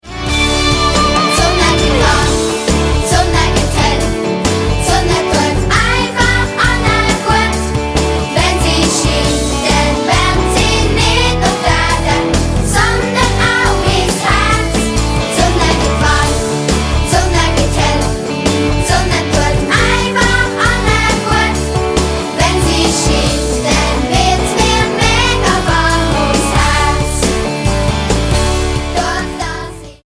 Mundart-Worshipsongs für Kids